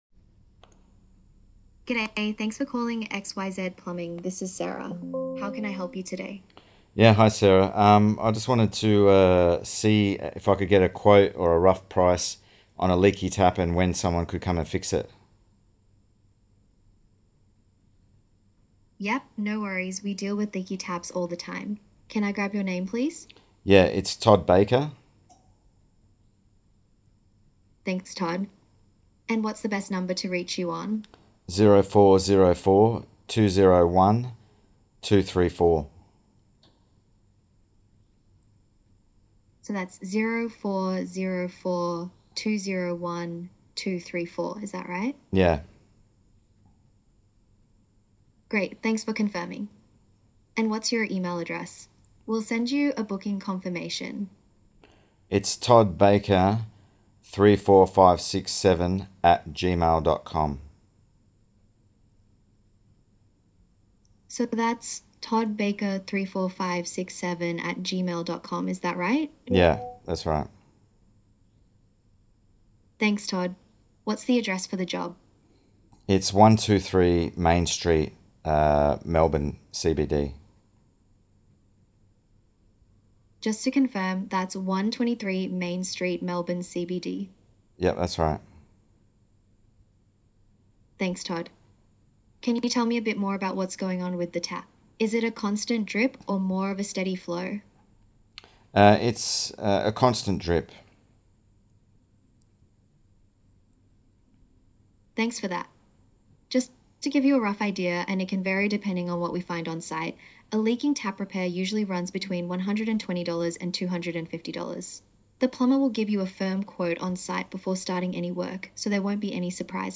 Press play and listen to Aenigma AI answer a real job call, ask the right questions, capture the details, and book the work — all without you touching your phone.
This is what your customers hear instead of your voicemail.